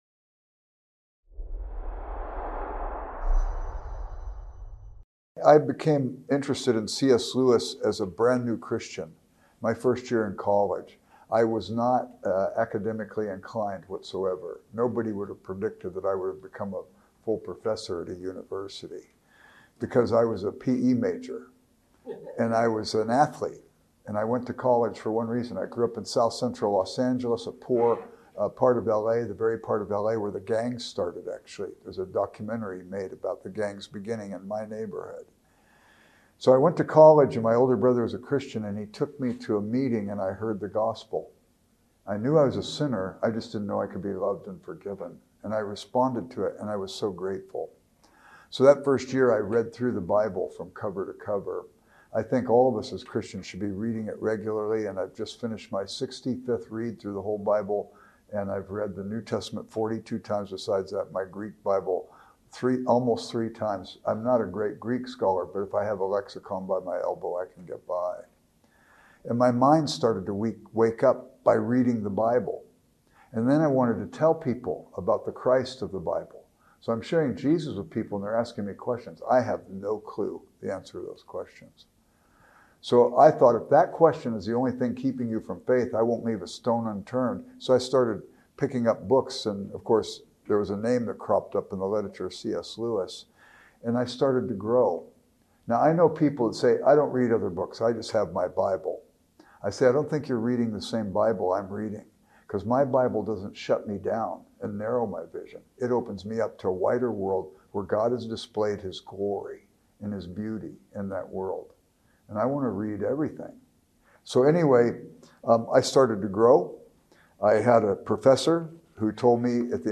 This talk explores Lewis’s development along these lines in the hopes of
Event: ELF Artists Network